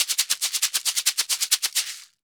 Maracas_Merengue 136-1.wav